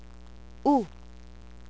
shuruk "u" as in moon